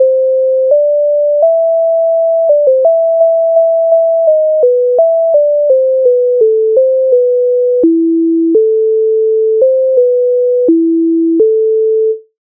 MIDI файл завантажено в тональності a-moll
Oй як миленькому постіль слати Українська народна пісня з обробок Леонтовича с. 101